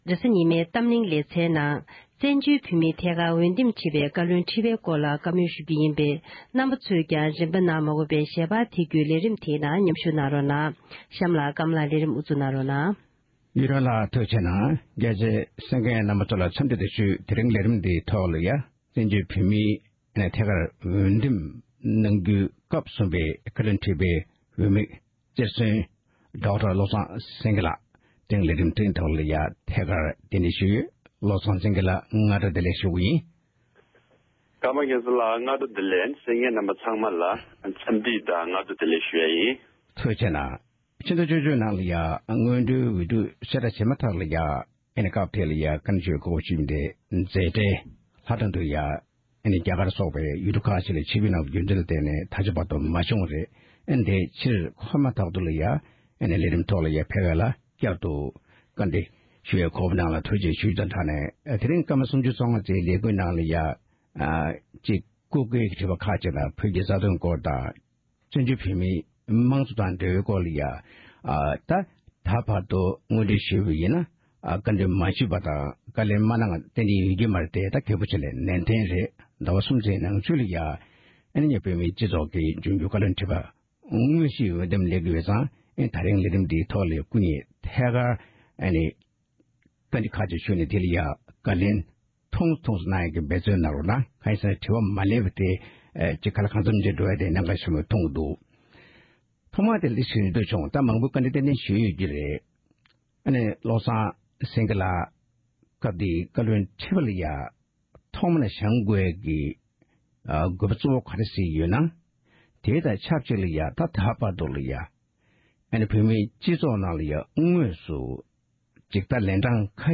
སྐབས་གསུམ་པའི་བཀའ་བློན་ཁྲི་པའི་དམིགས་རྩེར་སོན་བློ་བཟང་སེངྒེ་ལགས་ཀྱི་ལྷན་གླེང་བ།